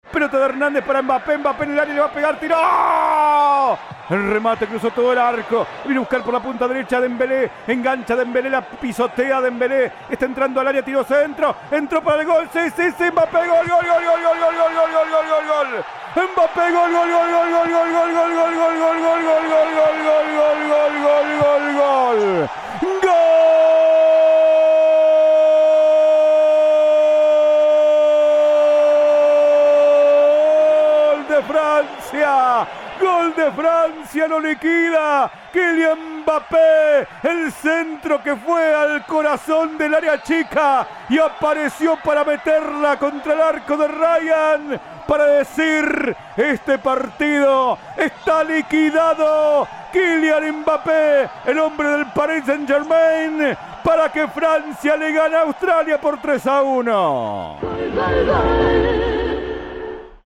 Audio. 3º gol de Francia a Australia - Mbappe (relato